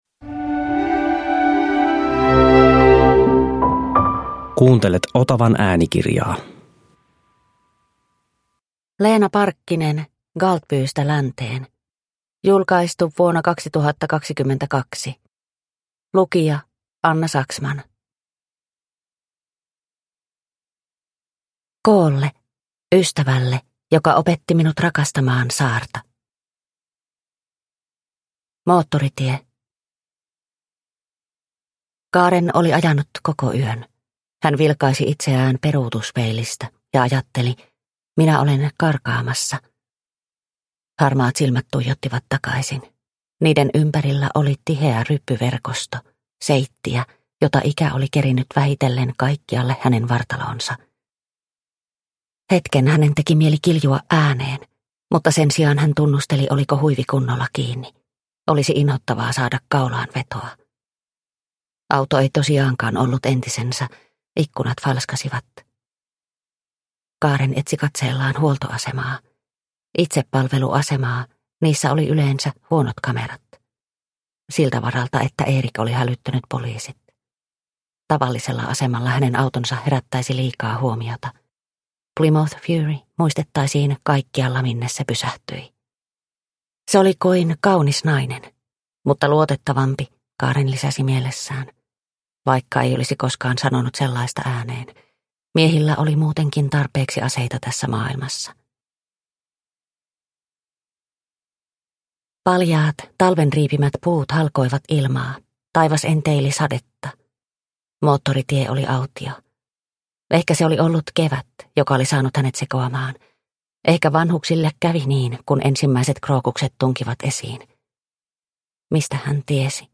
Galtbystä länteen – Ljudbok – Laddas ner